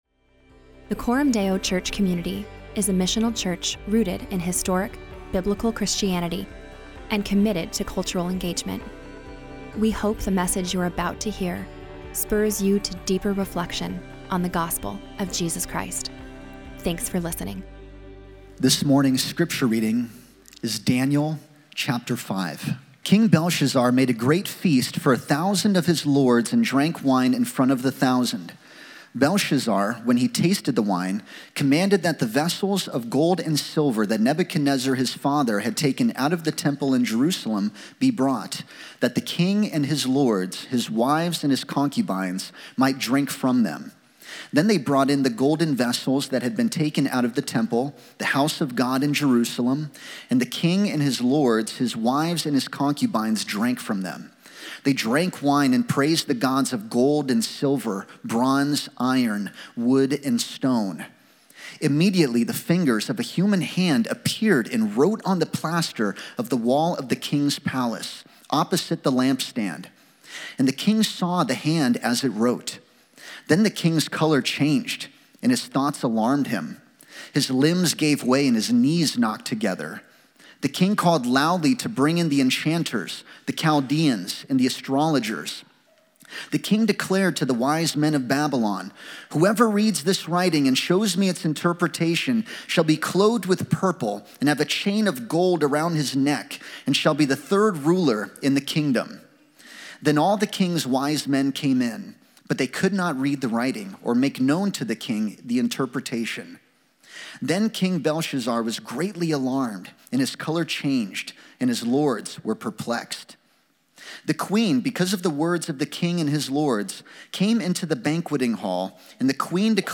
Weekly sermons from Coram Deo Church in Omaha, NE.